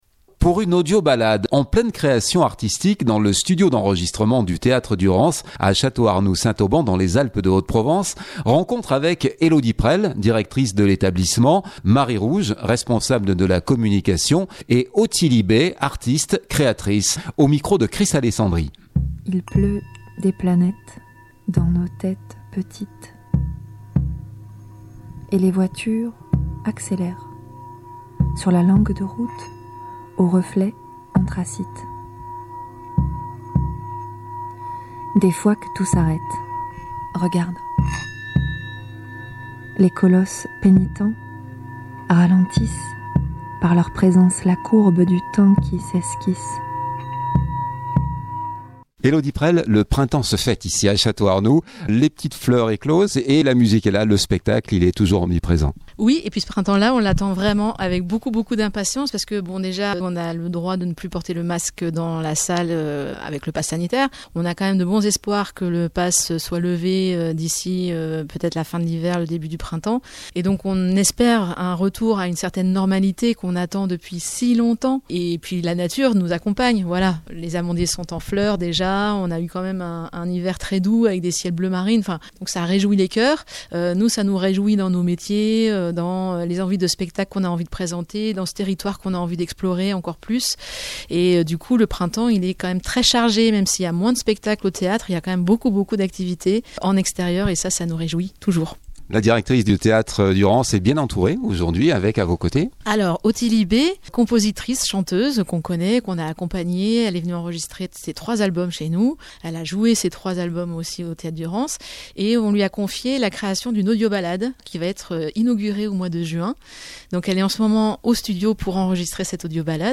En pleine création artistique dans le studio d’enregistrement du Théâtre Durance